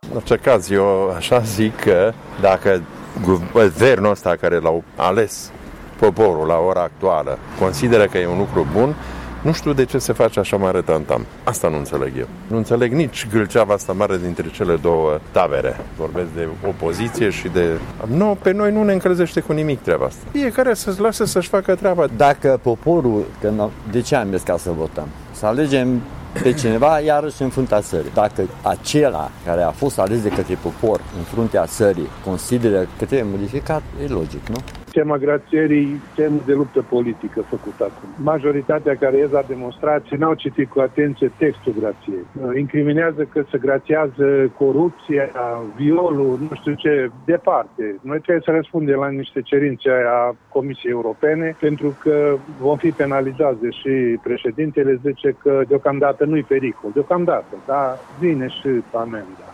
Târgumureşenii sunt nemulţumiţi de faptul că acest proiect creează tensiuni între taberele de la conducerea ţării.